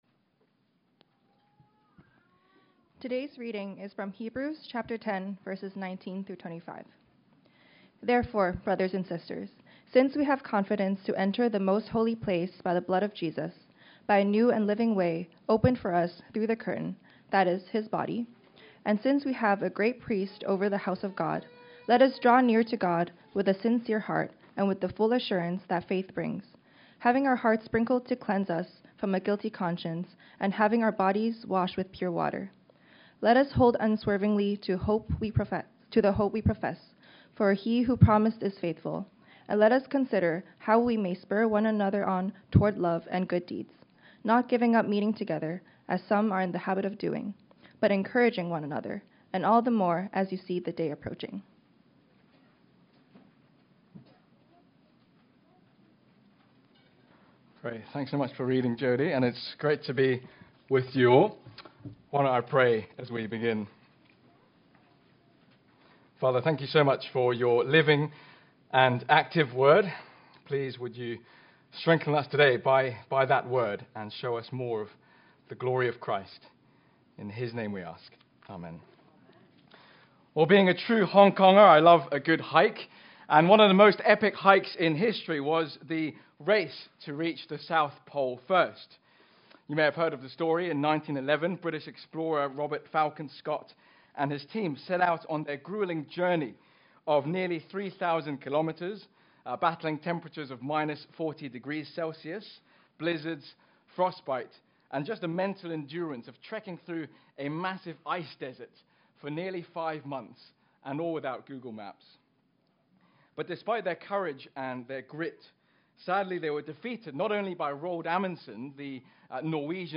Standalone Sermons